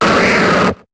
Cri d'Ossatueur dans Pokémon HOME.